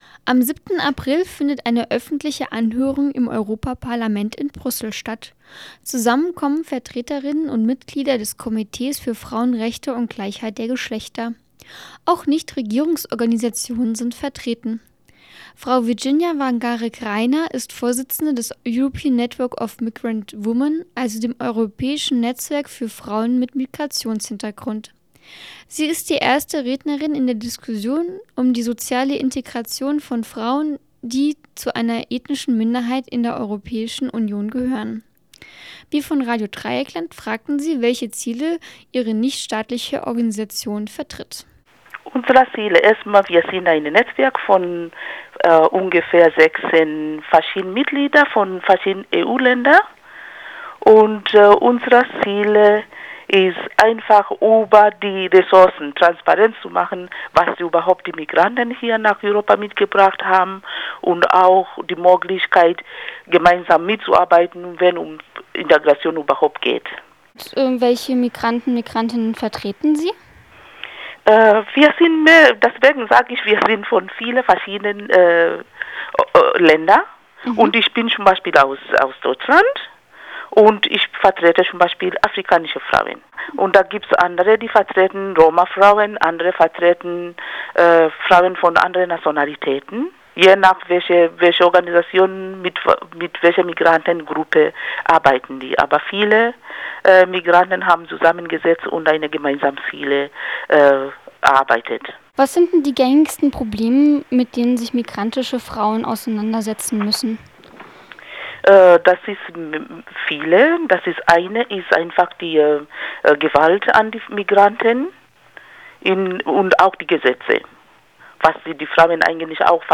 Intreview zu Frauenrechten in der EU